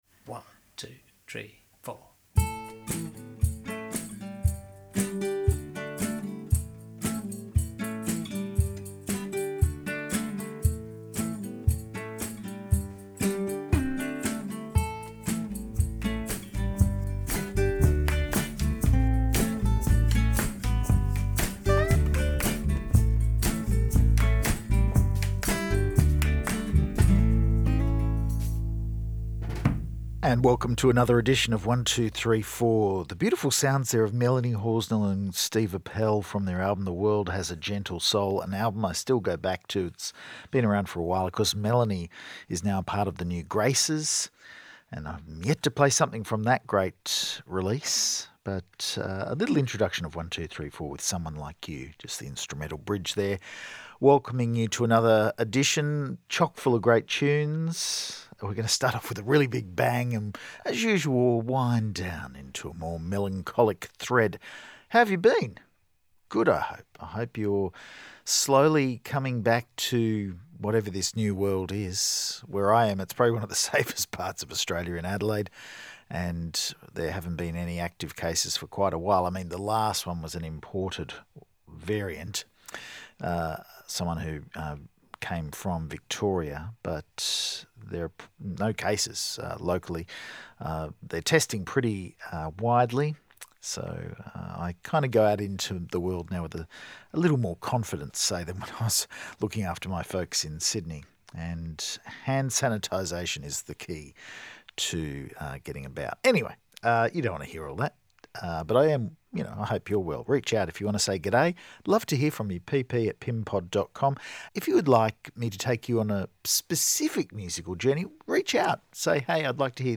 orchestral folk pop